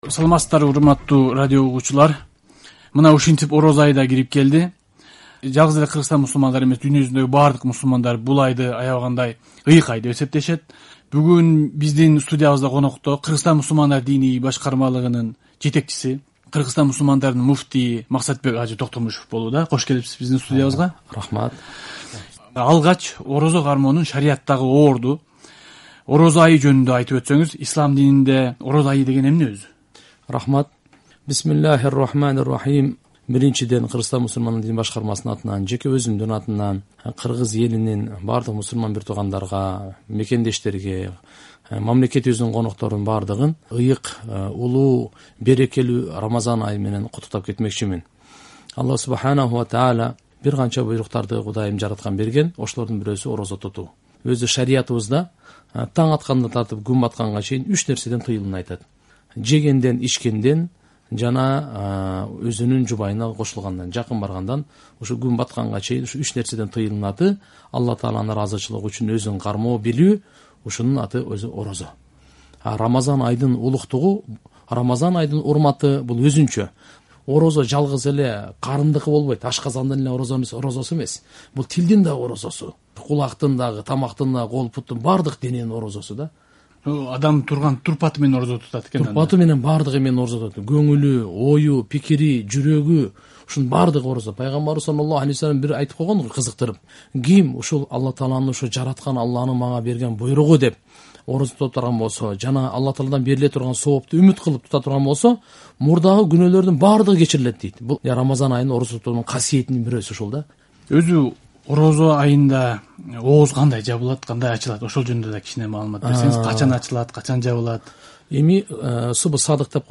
“Биз жана дин” түрмөгүбүздө Кыргызстандын муфтийи Максатбек ажы Токтомушев орозо тутуу жана рамазан айы тууралуу маек курмакчы.